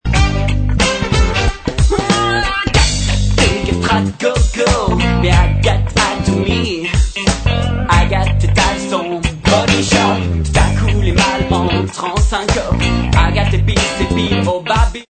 funk disco